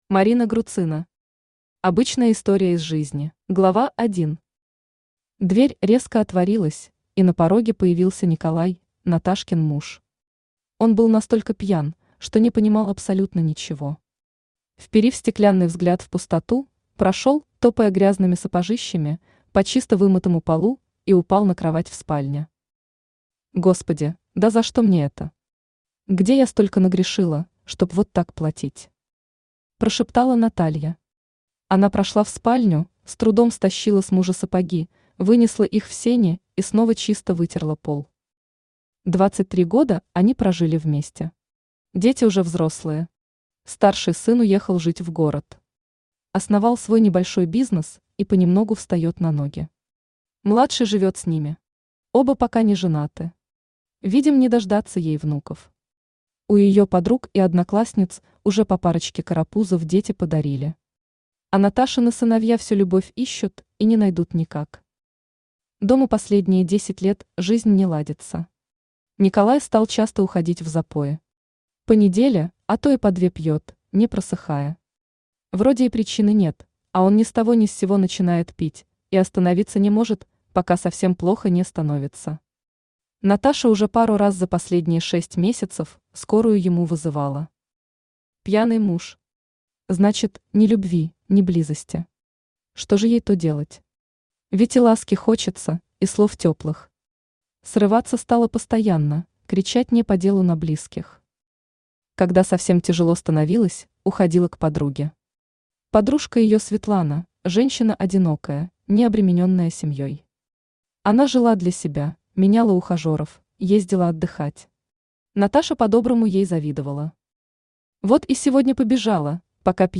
Аудиокнига Обычная история из жизни | Библиотека аудиокниг
Aудиокнига Обычная история из жизни Автор Марина Рудольфовна Груцина Читает аудиокнигу Авточтец ЛитРес.